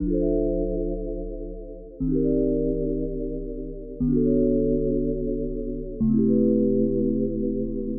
城镇钟声响起
描述：城镇钟声响起，Zoom H4录音，离钟楼约5米。 没有编辑。
标签： 敲钟 钟声 报时 集合
声道立体声